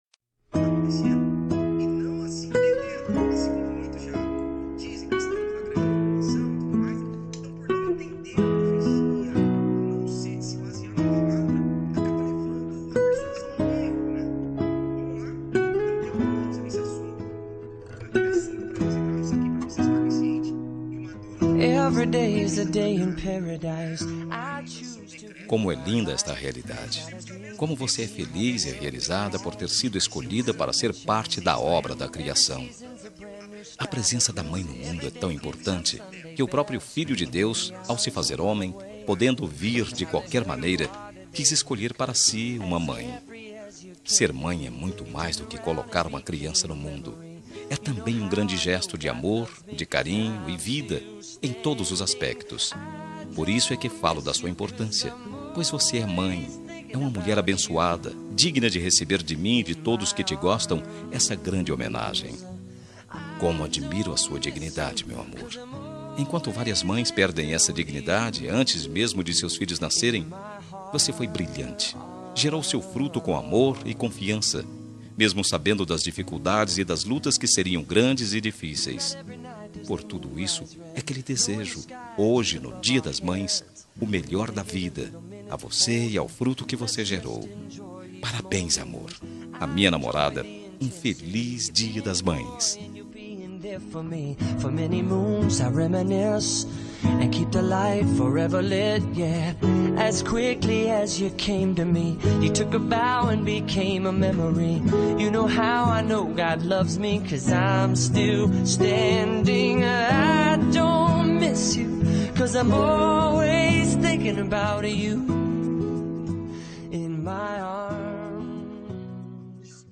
Dia das Mães – Para Namorada – Voz Masculina – Cód: 6511